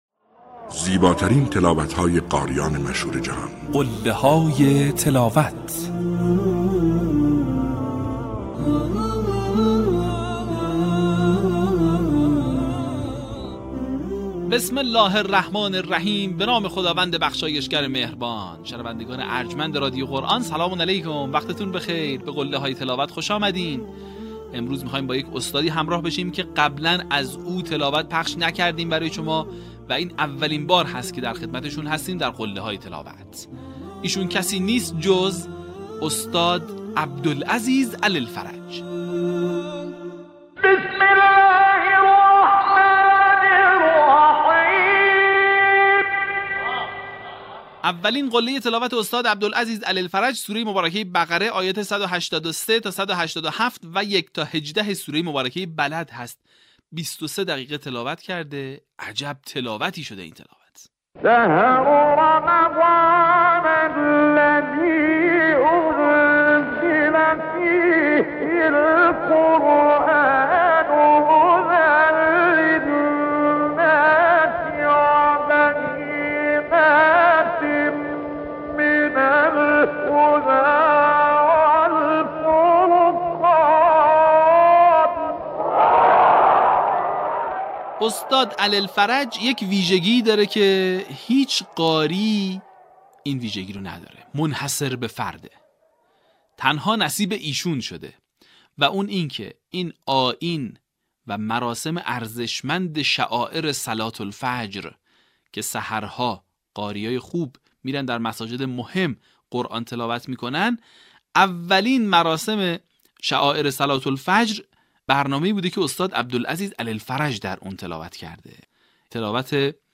در قسمت چهل‌وهشتم فراز‌های شنیدنی از تلاوت‌های به‌یاد ماندنی استاد عبدالعزیز علی الفرج را می‌شنوید.
برچسب ها: عبدالعزیز علی الفرج ، تلاوت ماندگار ، تلاوت تقلیدی ، قله های تلاوت